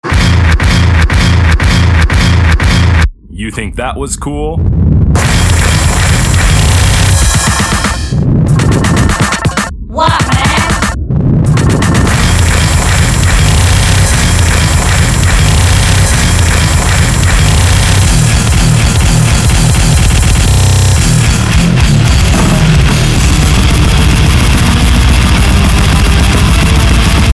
Aggressive music [mp3] | Project Perfect Mod
Well, my usual ambient stuff wouldn't work, so I started practicing on fast agressive music.
Very. some tracks seem to have messed up endings like a long roll-out or a sample that keeps running, don't worry about it
The sounds need to be refined, but I like the 4th one.